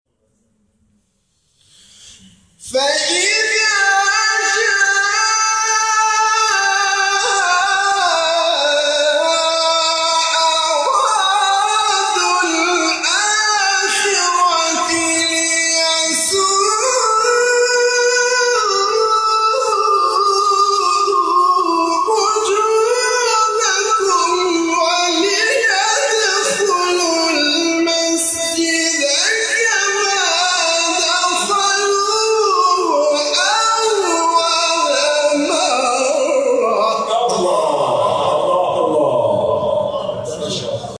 گروه شبکه اجتماعی: مقاطعی صوتی از تلاوت قاریان ممتاز کشور ارائه می‌شود.